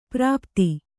♪ prāpti